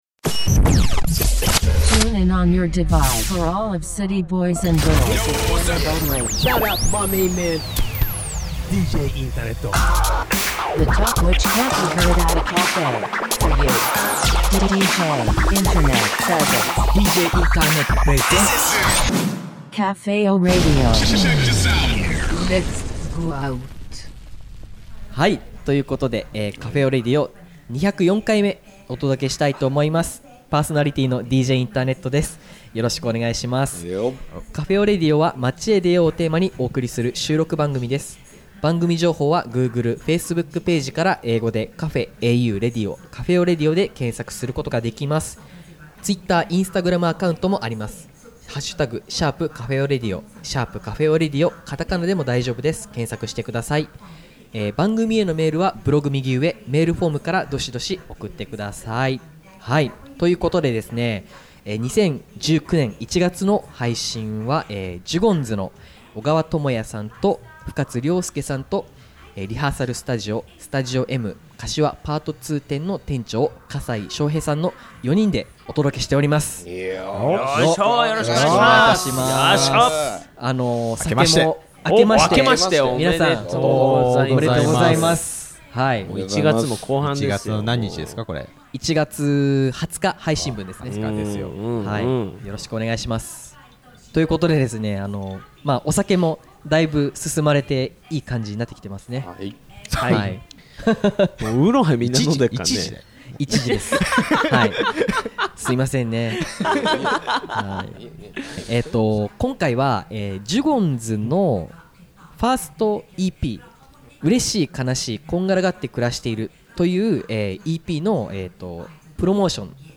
今回はLIVE音源をお届けしています！！